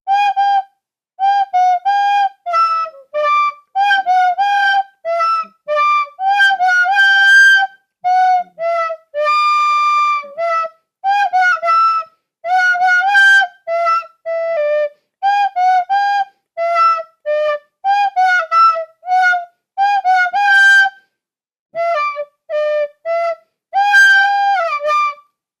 The national anthem of Chipland, Chipland, Fuck Yeah!